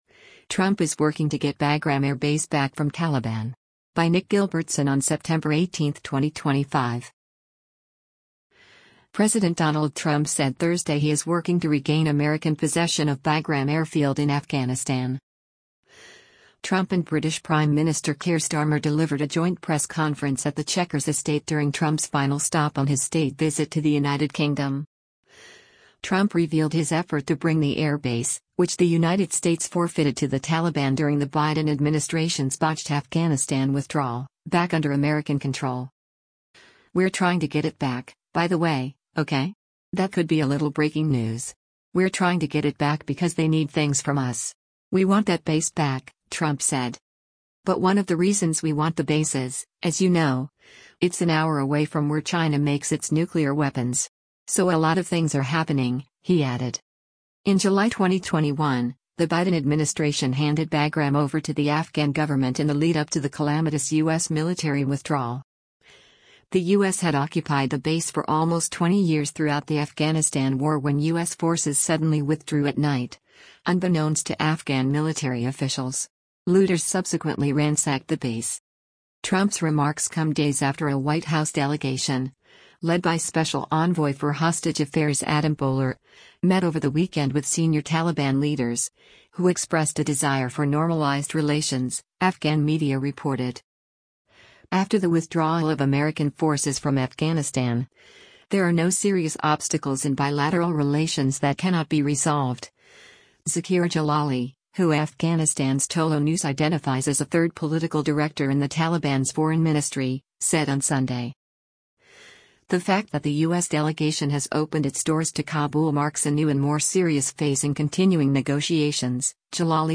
Trump and British Prime Minister Keir Starmer delivered a joint press conference at the Chequers Estate during Trump’s final stop on his state visit to the United Kingdom.